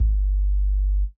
3kik.wav